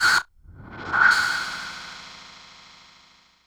synthFX02.wav